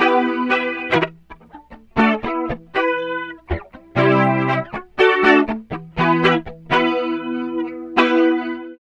78 GTR 5  -L.wav